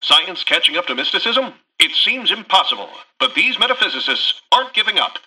Newscaster_headline_50.mp3